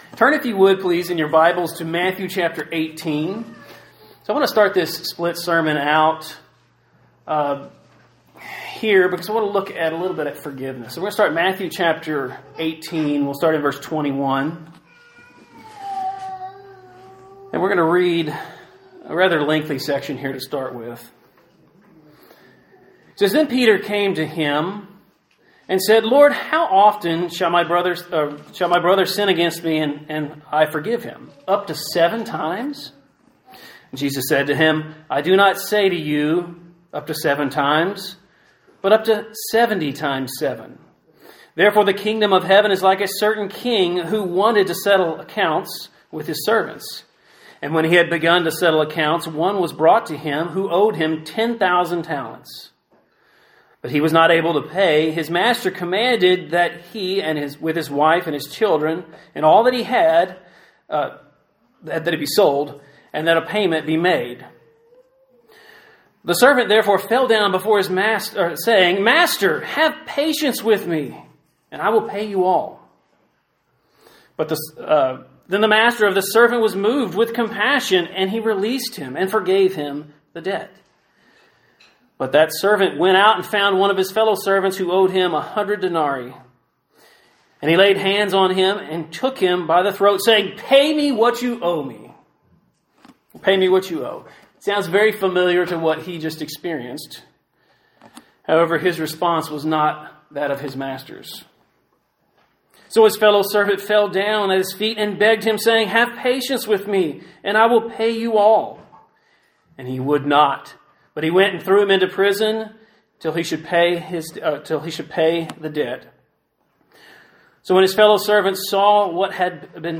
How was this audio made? Given in New Jersey - North New York City, NY